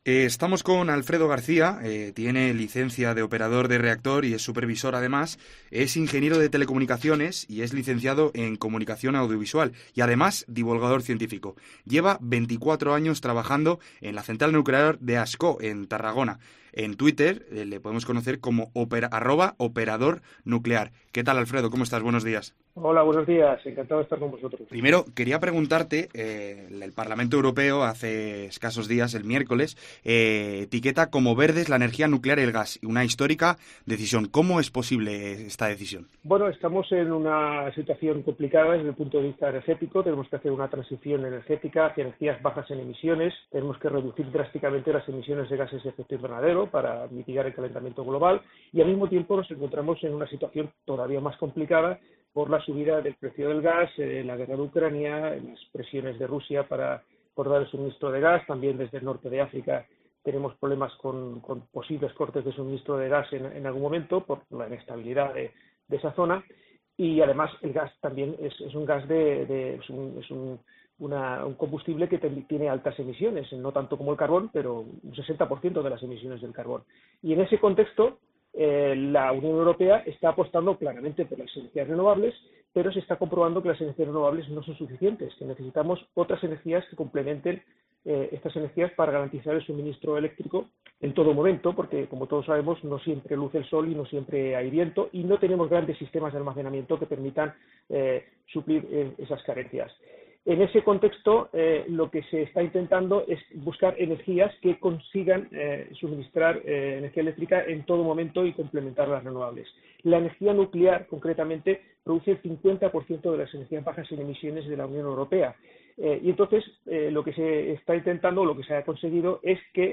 En COPE hemos podido hablar con él sobre la decisión del Parlamento Europeo de etiquetar a la energía nuclear y al gas como energías verdes.